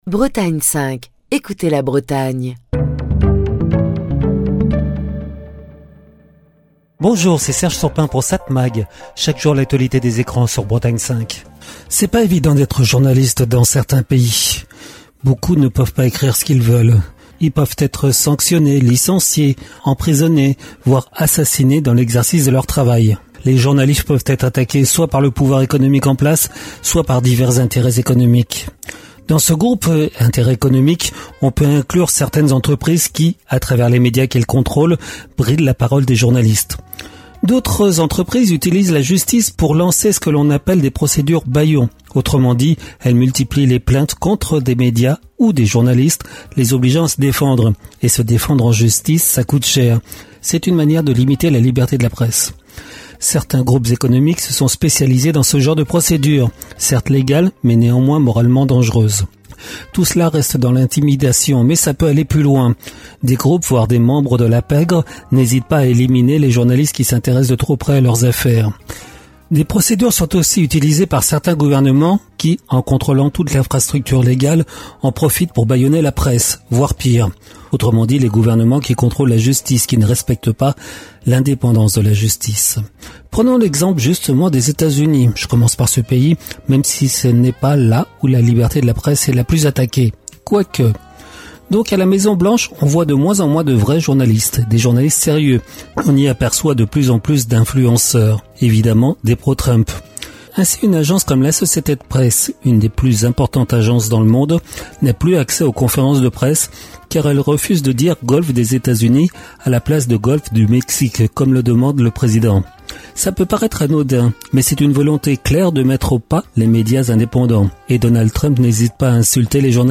Chronique du 27 mai 2025.